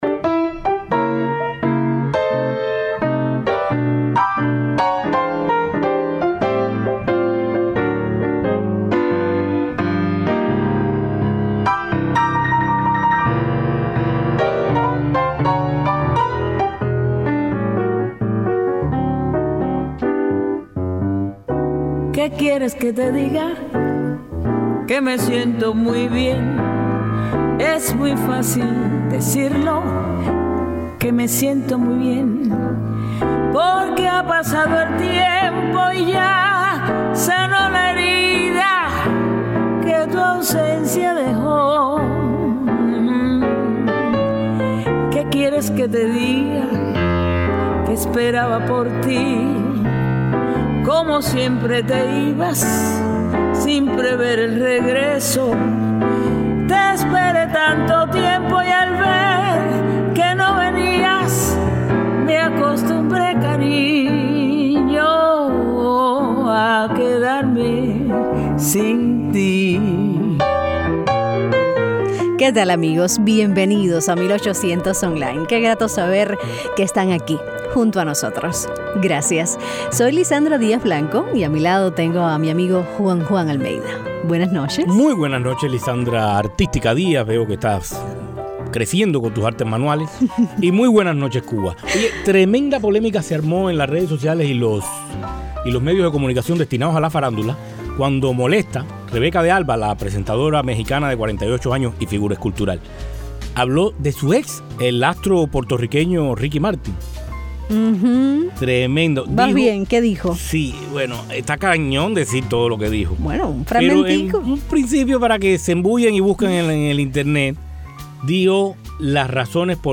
También declama y ríe.